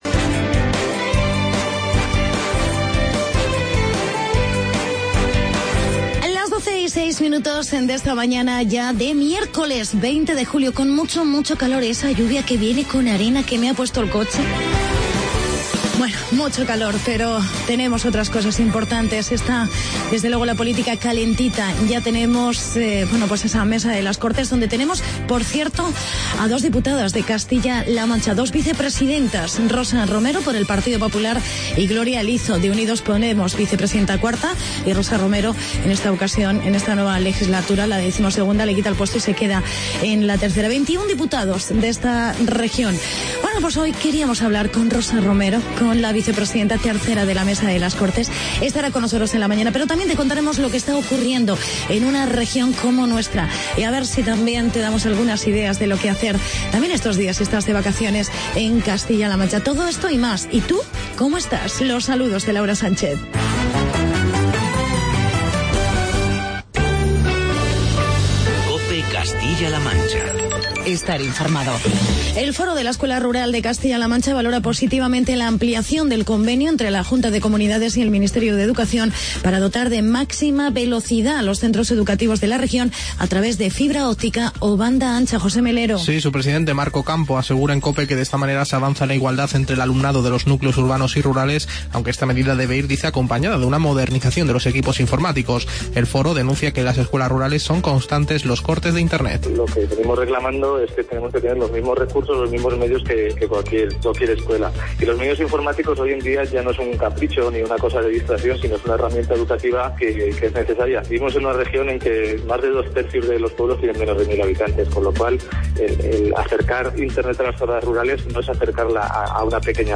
Entrevistamos a Rosa Romero, vicepresidenta 3ª de la Mesa de las Cortes del Congreso de los Diputados.